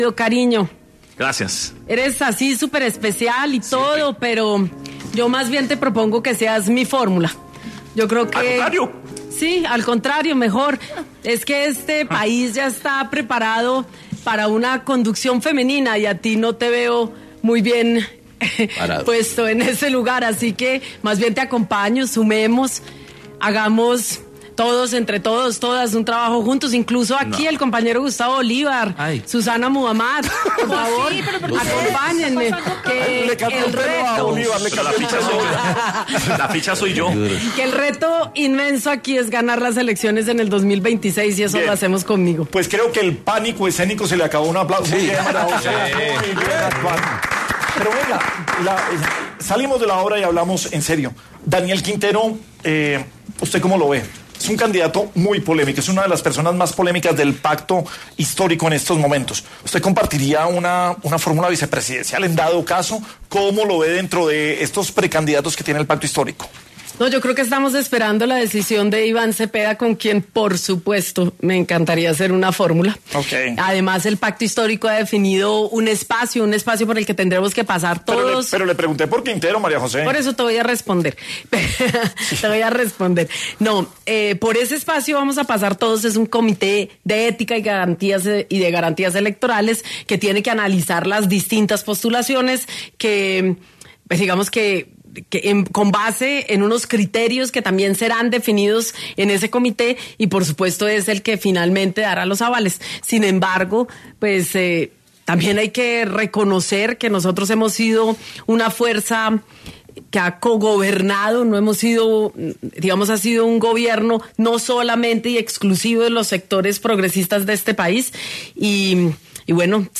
En Sin Anestesia de La Luciérnaga estuvo la precandidata presidencial María José Pizarro para hablar acerca de su fórmula para las elecciones 2026